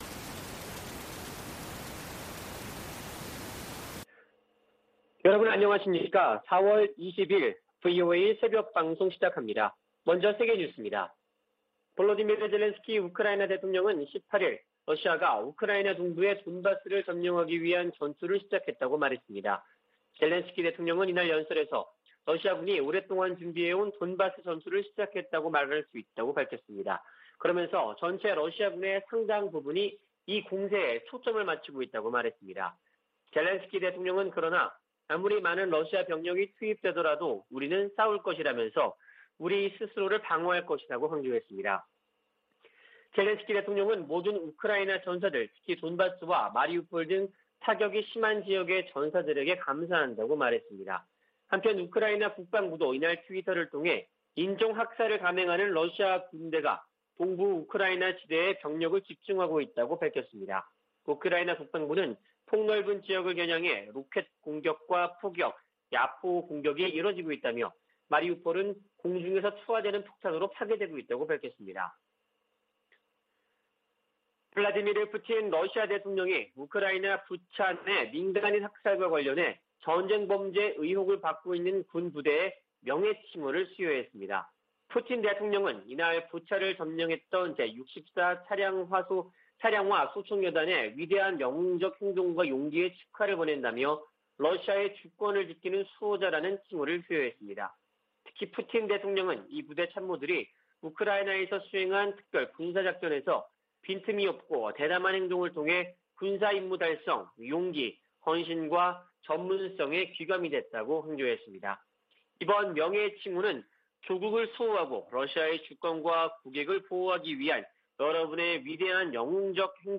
VOA 한국어 '출발 뉴스 쇼', 2022년 4월 20일 방송입니다. 미 국무부는 북한이 도발을 계속하면 상응 조치를 이어갈 것이라고 경고했습니다.